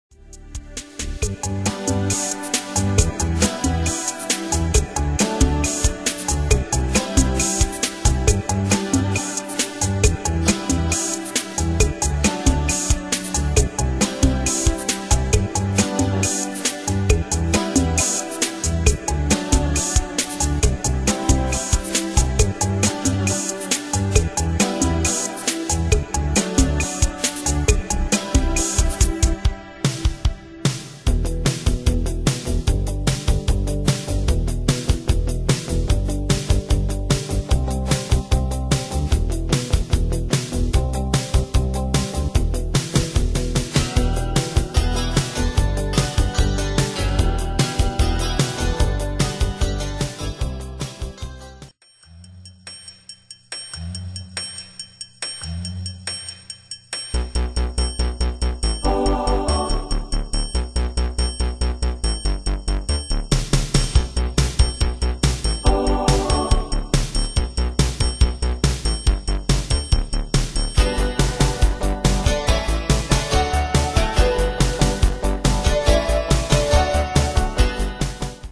Style: Medley Année/Year: 2007 Tempo: 136 Durée/Time: 8.42
Danse/Dance: PopRock Cat Id.
Pro Backing Tracks